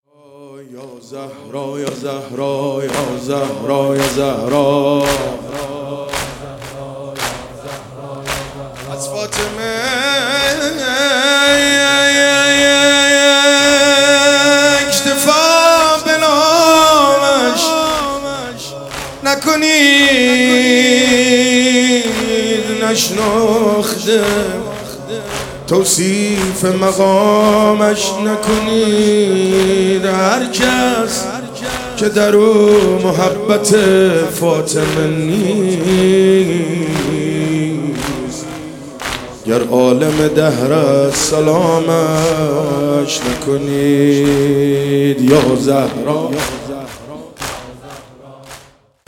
فاطمیه 95
مداحی